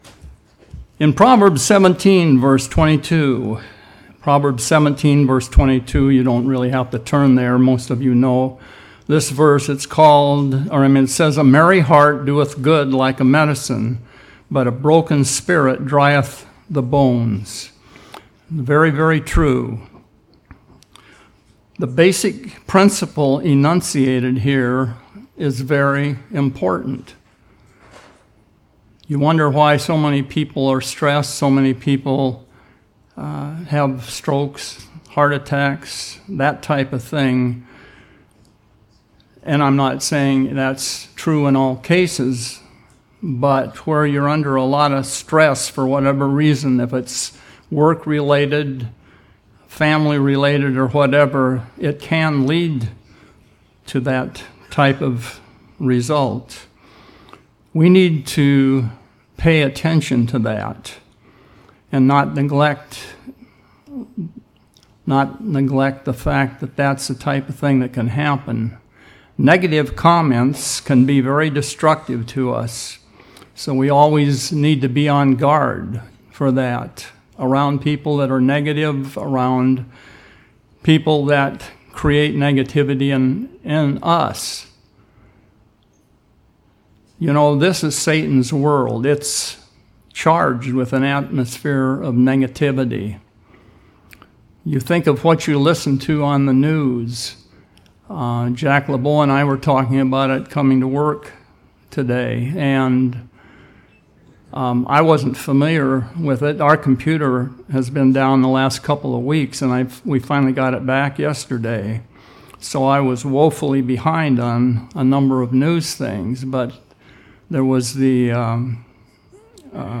Sermons
Given in Seattle, WA